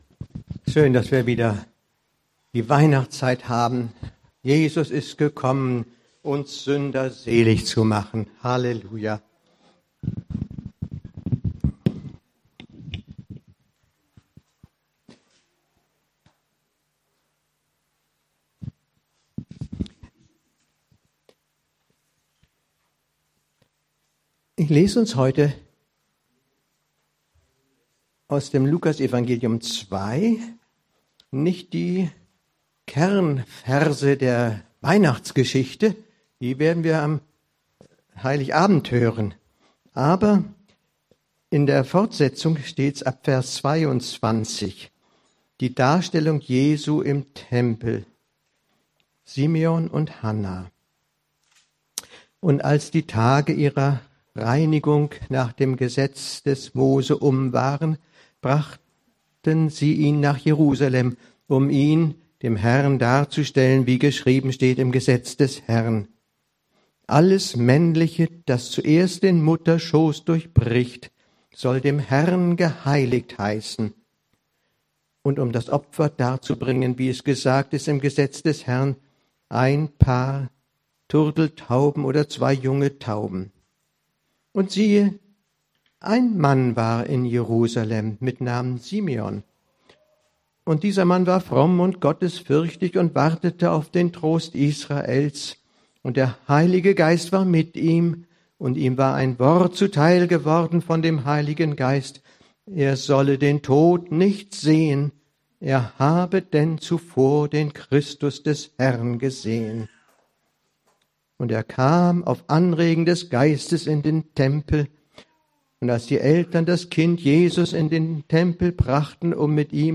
Die Predigt reflektiert über die Erwartungshaltung auf das Kommen des Messias und wie diese Erwartung auch heute noch in Israel und in der christlichen Gemeinschaft besteht. Die Darstellung Jesu im Tempel und die Prophetenhandel von Simeon und Anna werden als Beispiele für die tiefere innige Gemeinschaft mit Gott und dem Heiligen Geist dargestellt. Die Predigt betont die Not der Welt und die Erwartung nach dem Kommen Jesu, um die Not zu beenden.